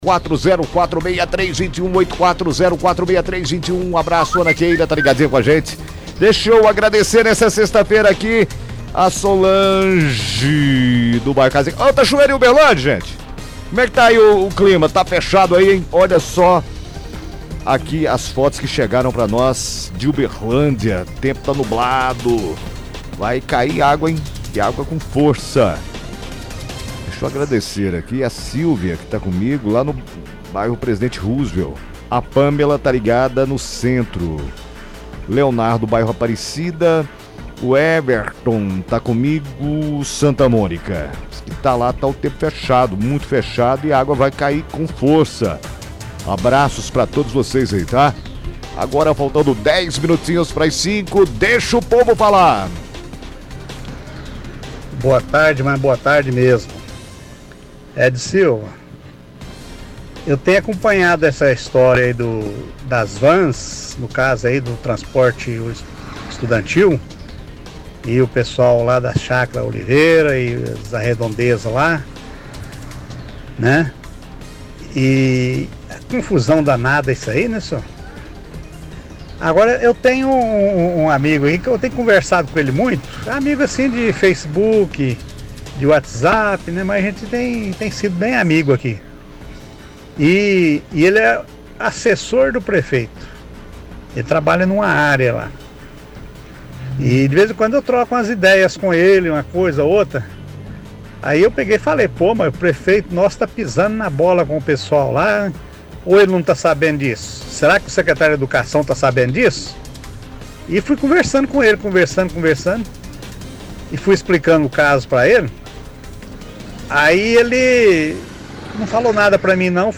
Ligação Ouvinte – Vans e dinheiro do Fundeb
ouvinte